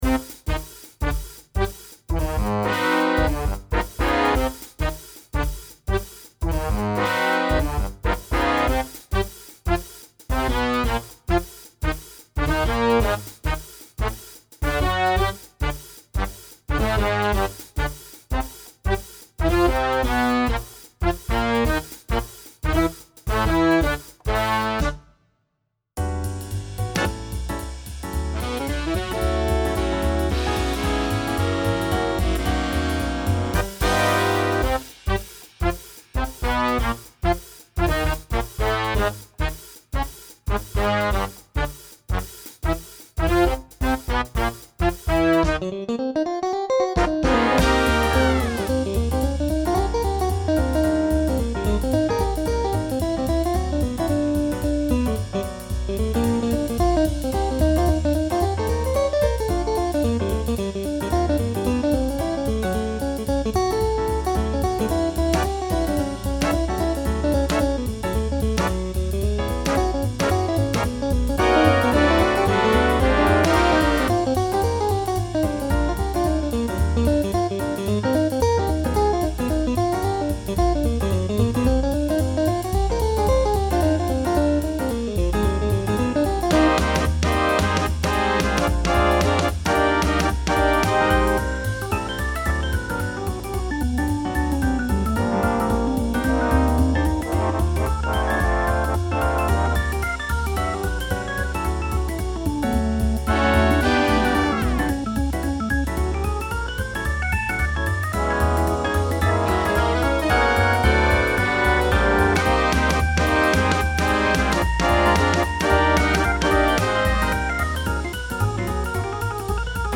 Fast or medium swing. Solos for Alto sax and Trumpet.